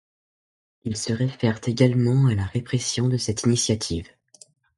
Pronounced as (IPA) /ʁe.pʁɛ.sjɔ̃/